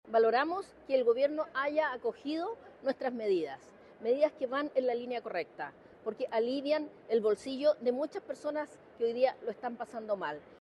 En ese contexto, la jefa de bancada del gremialismo, Flor Weisse, valoró que el Gobierno haya considerado estas propuestas dentro de los anuncios realizados.